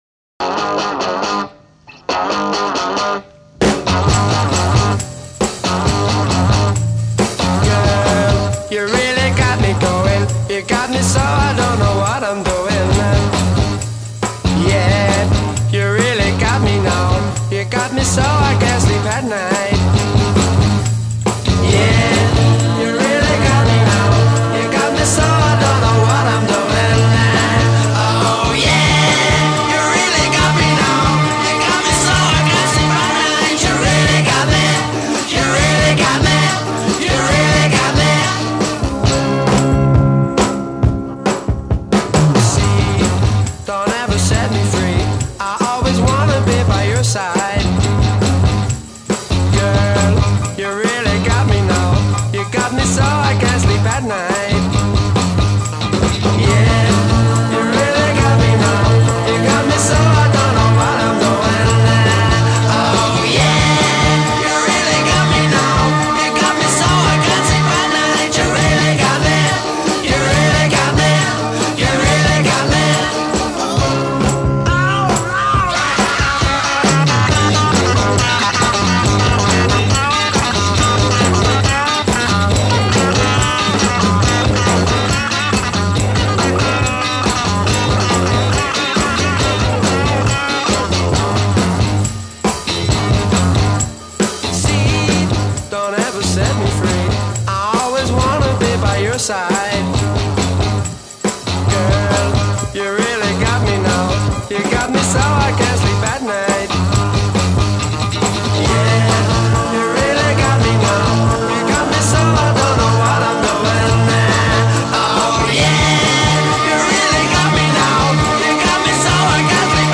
Recorded mid July 1964 at IBC Studios, London.
Intro 0:00 8 guitar chords, add ensemble
A* verse p1 : 20 guitar solo on tonic
coda   4 guitar chords w/ bass and drums in unison b